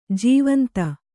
♪ jīvanta